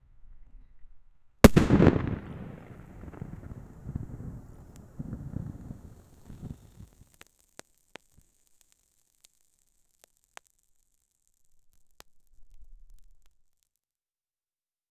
fire-arrow-hit-small-expl-bs4xc24y.wav